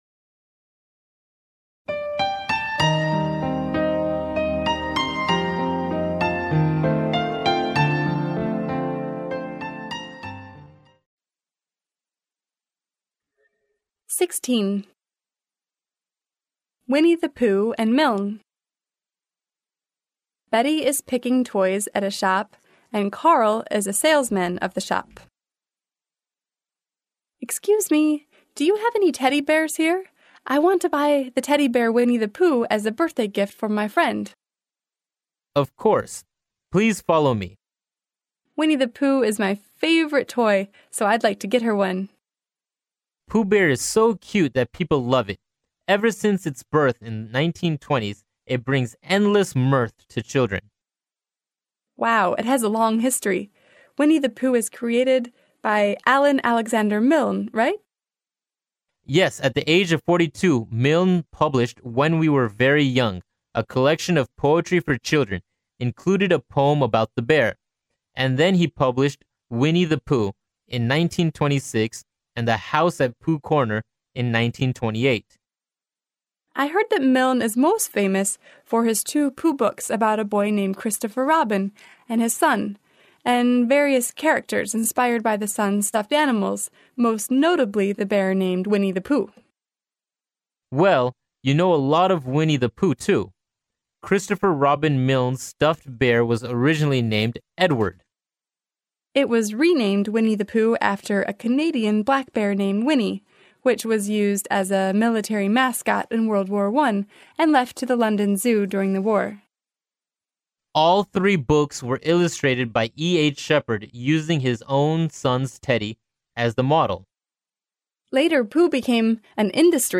剑桥大学校园英语情景对话16：《小熊维尼》与米尔恩（mp3+中英）